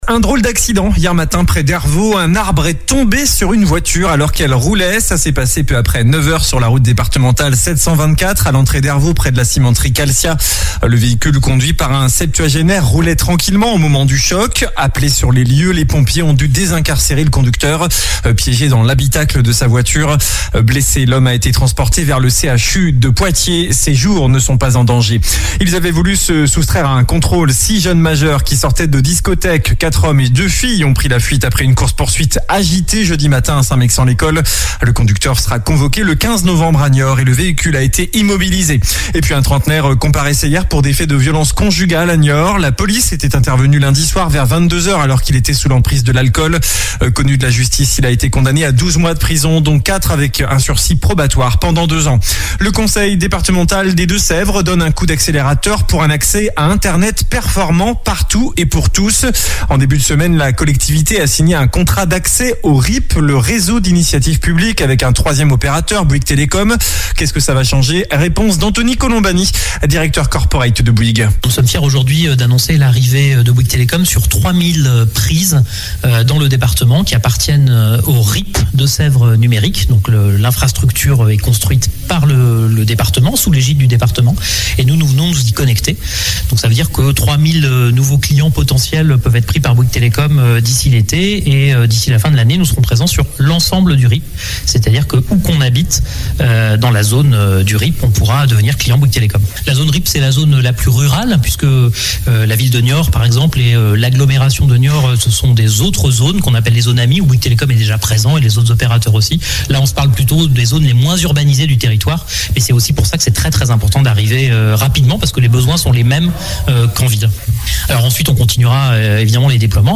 Journal du samedi matin 28 mai